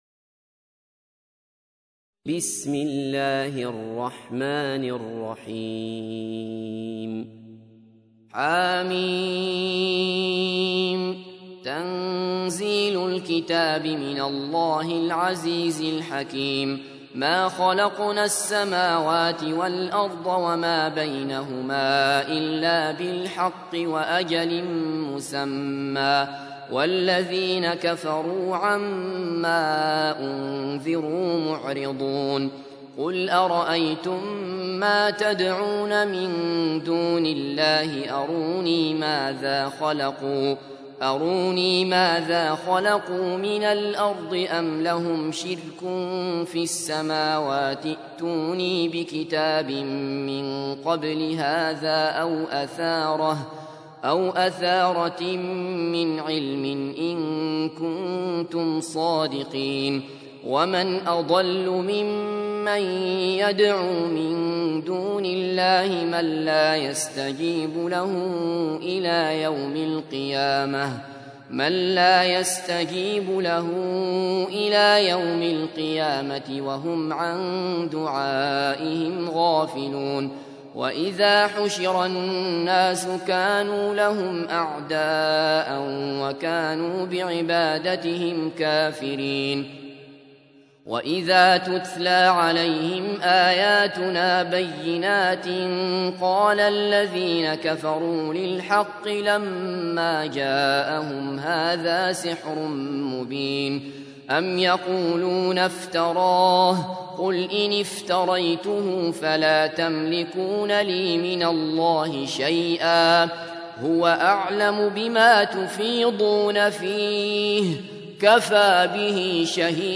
تحميل : 46. سورة الأحقاف / القارئ عبد الله بصفر / القرآن الكريم / موقع يا حسين